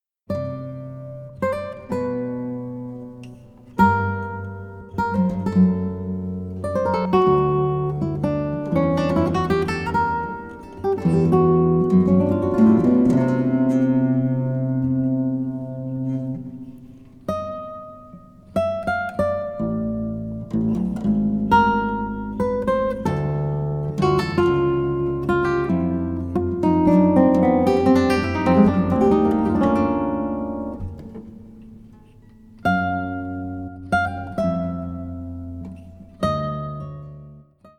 16-string classical guitar, 16-string Contraguitar